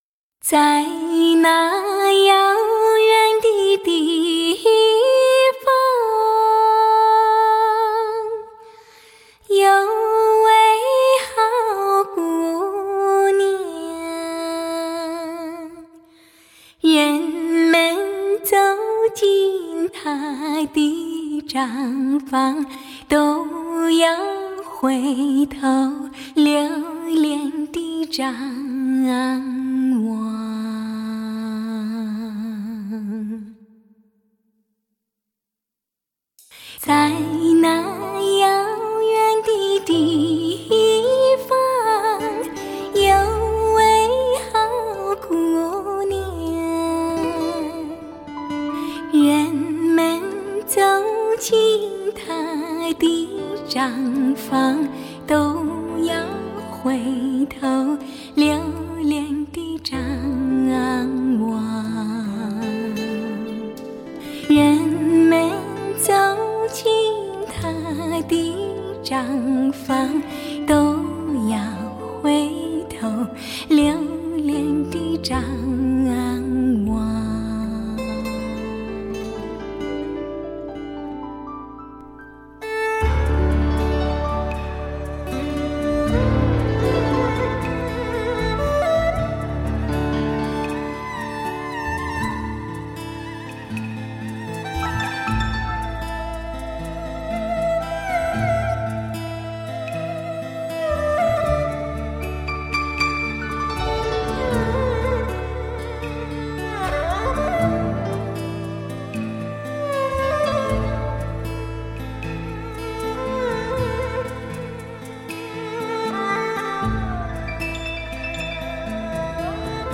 香港至尊发烧友极力推荐——经典流行歌极品